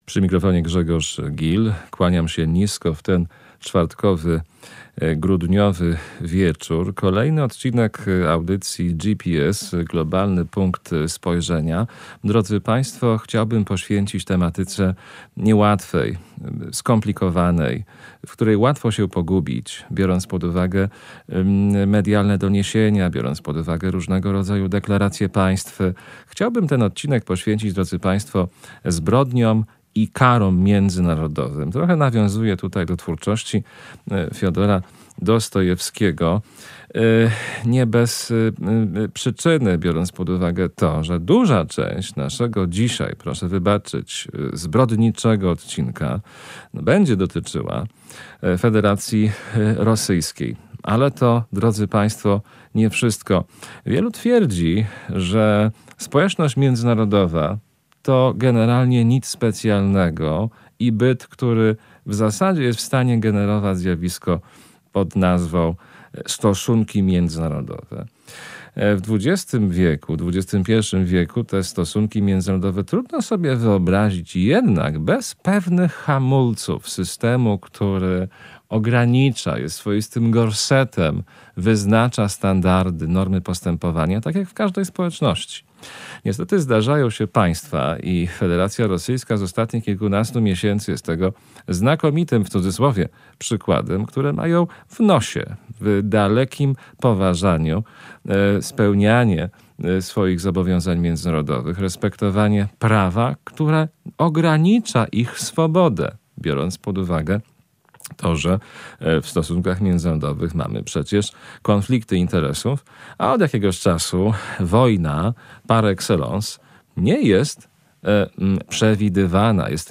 Na rozmowę